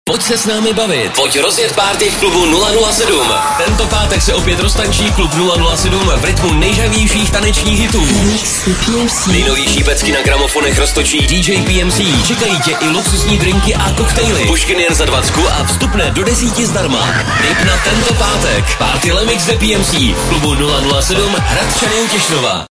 pravidelná taneční párty Freeradia 107 FM - energický mix s pořádnou porcí nejžhavějších hitů